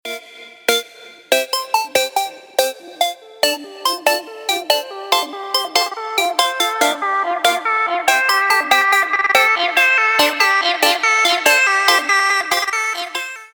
• Качество: 320, Stereo
Electronic
EDM
без слов
club